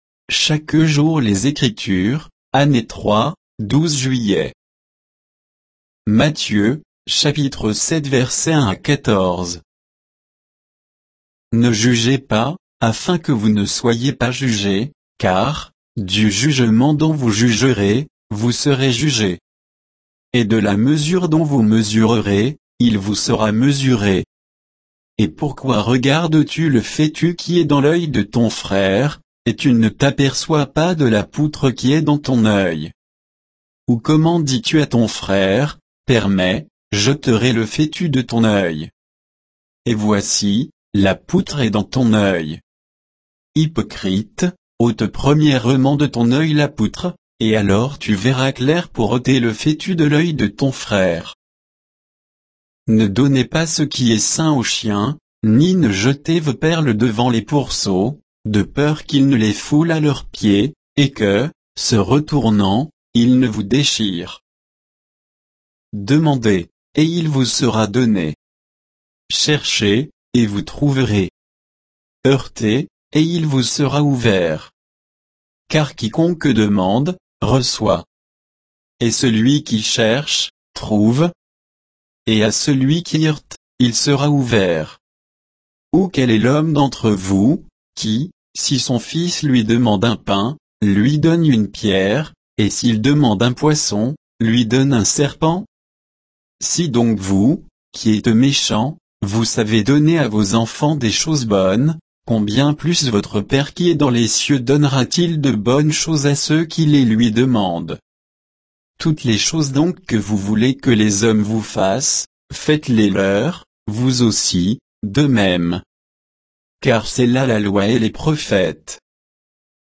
Méditation quoditienne de Chaque jour les Écritures sur Matthieu 7